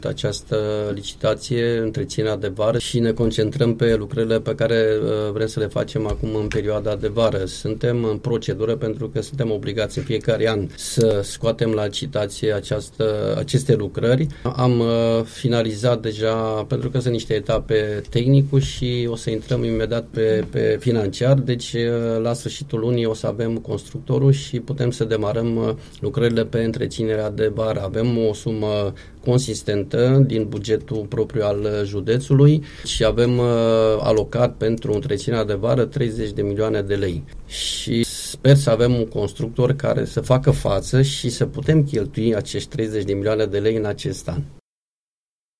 Vicepreședintele Consiliului Județean Alba, Marius Hategan a precizat, la Unirea FM, că în perioada următoare va fi cunoscut și constructorul care va executa aceste lucrări pe drumurile județene.